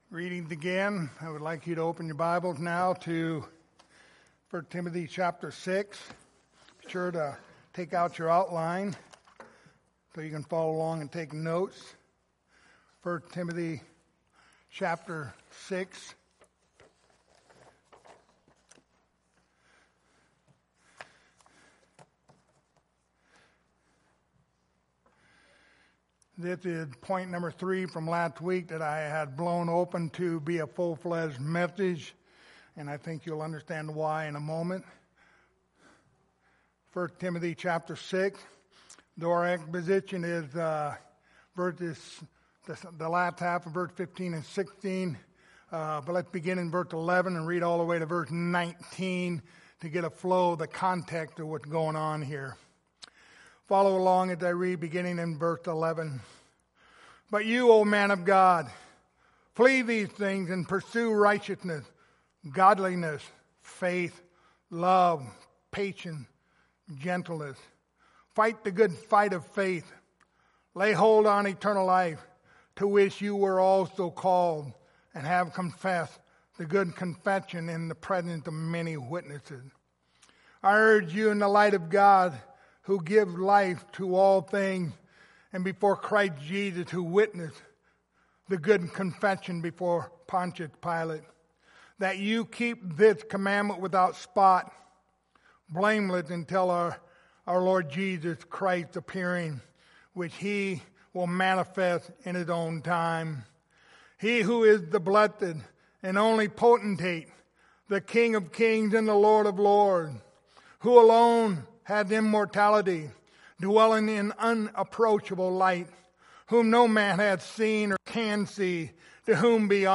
Passage: 1 Timothy 6:15-16 Service Type: Sunday Morning